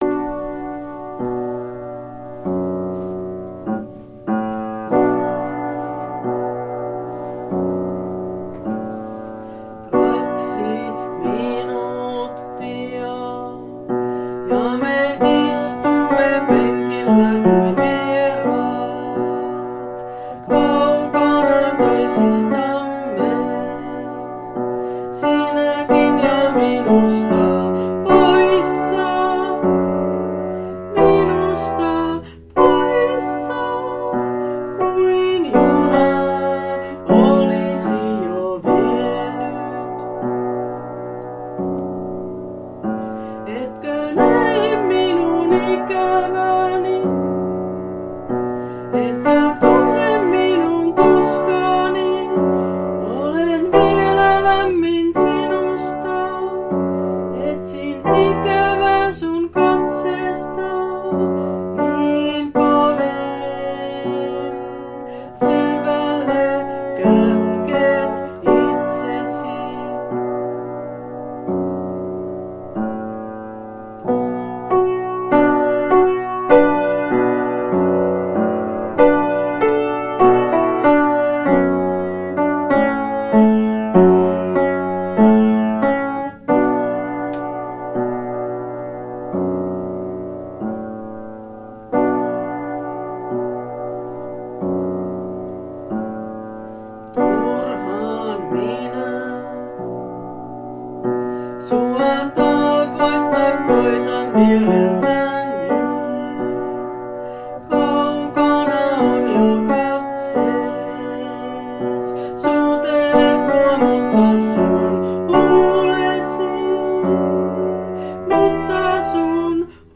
Säestystapa ja välisoitot tein sitten vähän myöhemmin.
Laulu